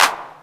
Index of /90_sSampleCDs/USB Soundscan vol.51 - House Side Of 2 Step [AKAI] 1CD/Partition C/07-CLAPS